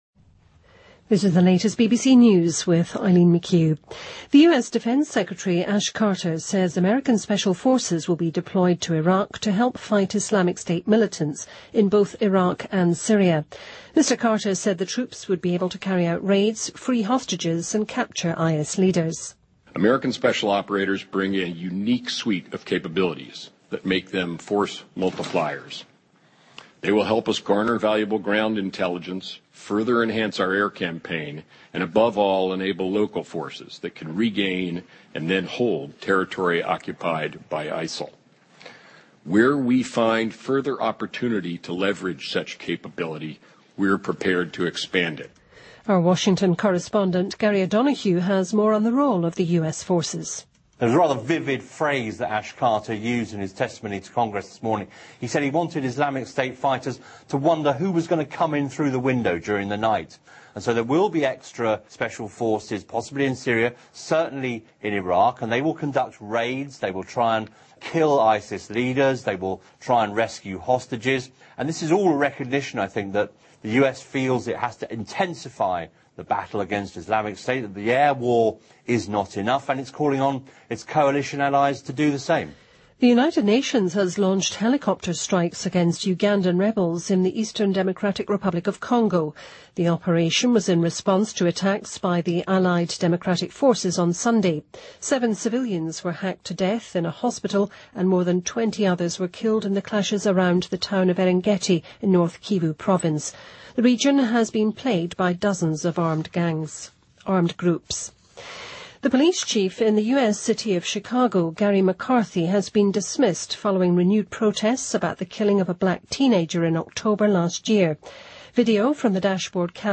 BBC news,美将向伊拉克派遣特种部队